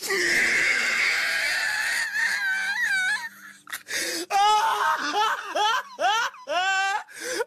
laugh2.wav